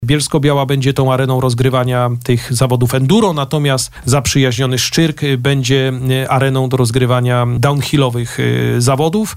– Organizacja tej imprezy jest już pewna […]. Kiedy popatrzymy na kalendarz tych wydarzeń i widzimy takie kraje jak: Brazylia, Kanada, Stany Zjednoczone, Francja, Włochy – to miło jest też popatrzeć, że Polskę reprezentuje Bielsko-Biała – mówił na naszej antenie Adam Ruśniak, zastępca prezydenta miasta.